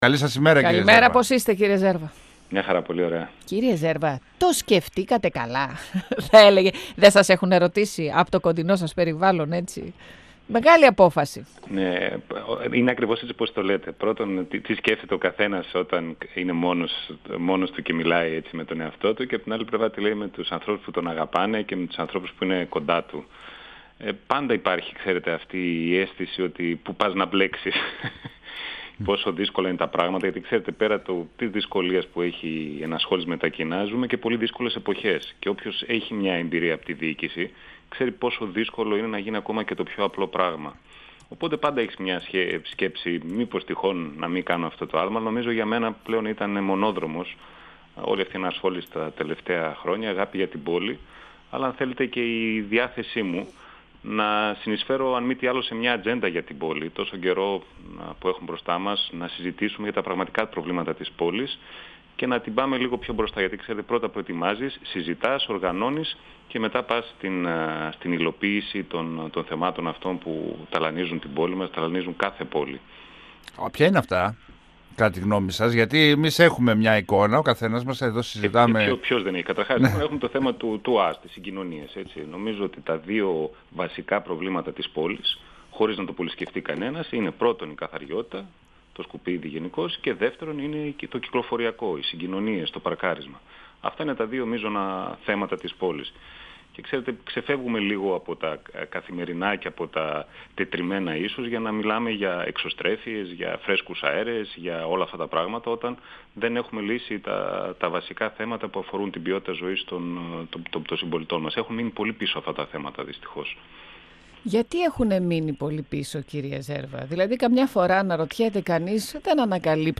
Μονόδρομο χαρακτήρισε την υποψηφιότητά του για τον Δήμο Θεσσαλονίκης, ο δημοτικός σύμβουλος Κωνσταντίνος Ζέρβας μιλώντας στον 102FM του Ραδιοφωνικού Σταθμού Μακεδονίας της ΕΡΤ3. Ο κ. Ζέρβας μίλησε για έλλειμμα εξουσίας στον δήμο, και για ουσιαστικές παρεμβάσεις που έχουν να γίνουν από το 1997. Για τη συμφωνία με τα Σκόπια ο υποψήφιος δήμαρχος δήλωσε ότι είναι μια κακή συμφωνία που δεν έχει ανταποδοτικά οφέλη για τη χώρα μας.
Για τη συμφωνία με τα Σκόπια ο υποψήφιος δήμαρχος δήλωσε ότι είναι μια κακή συμφωνία που δεν έχει ανταποδοτικά οφέλη για τη χώρα μας. 102FM Συνεντεύξεις ΕΡΤ3